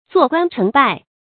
注音：ㄗㄨㄛˋ ㄍㄨㄢ ㄔㄥˊ ㄅㄞˋ
坐觀成敗的讀法